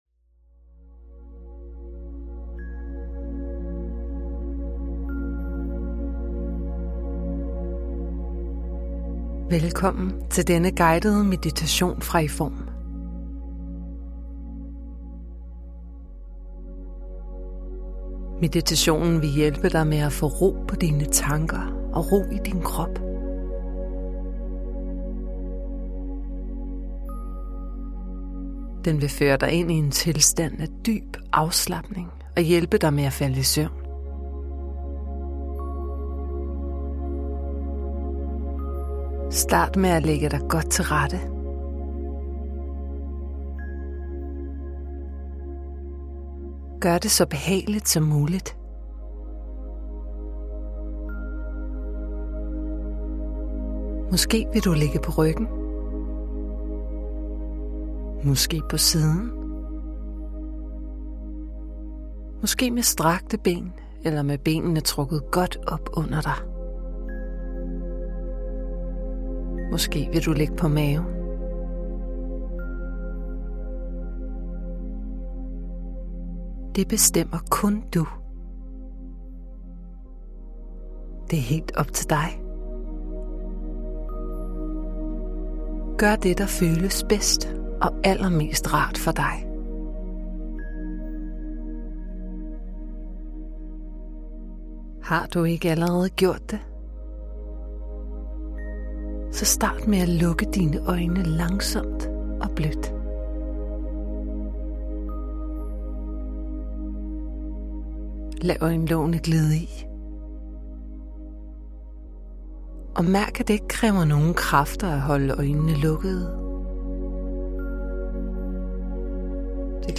Slip bekymringerne, og fald hurtigere i søvn med denne rolige meditation, der også indeholder en kropsscanning.
Du bliver guidet hele vejen – alt, du skal gøre, er at lægge dig godt til rette og lytte.
De sidste fem minutter indeholder ingen speak, men er blot en fortsættelse af det rolige lydspor.